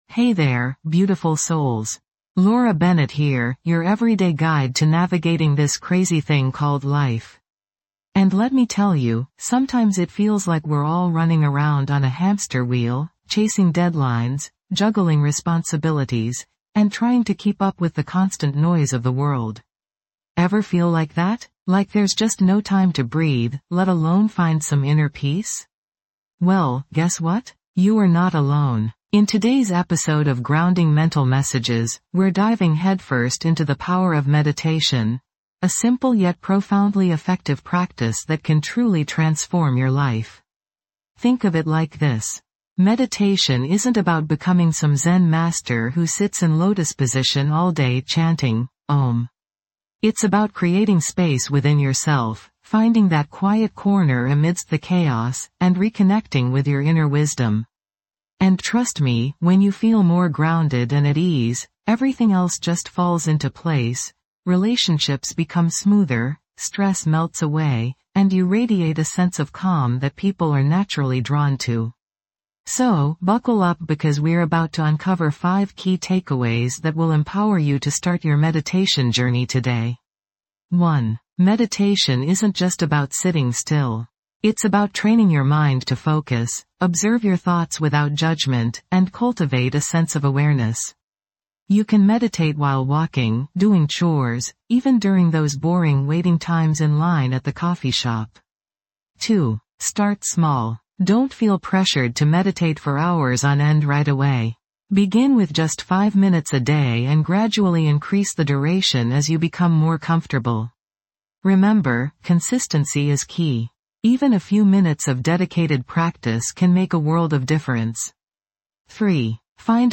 Through soothing guided meditations, mindfulness exercises, and calming soundscapes, this podcast offers practical tools to navigate the challenges of everyday life. Each episode is designed to anchor you in the present moment, reducing stress, promoting self-awareness, and fostering a deeper sense of well-being.